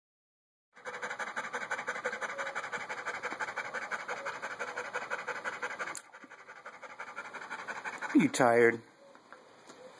panting_sample.wav